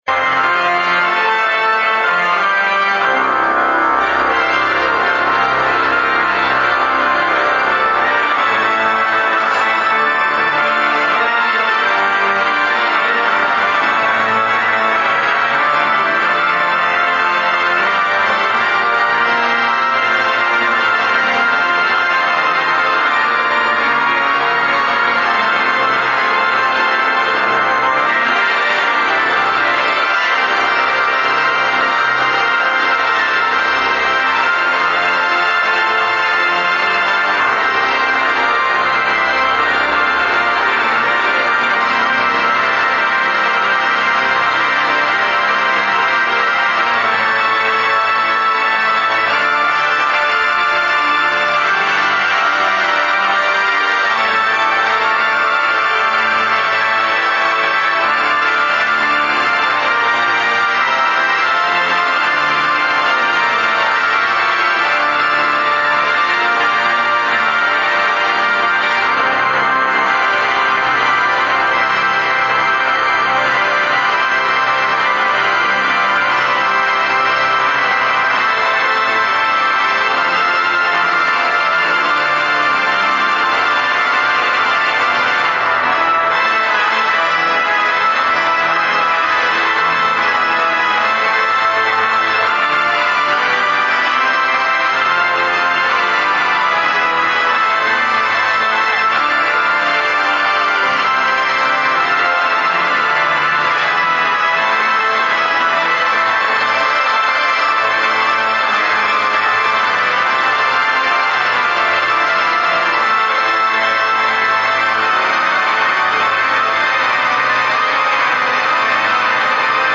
Gallery >> Audio >> Audio2013 >> Ingresso Arcivescovo Pennisi >> mp3-Finale Organo
mp3-Finale Organo
mp3-Finale Organo.mp3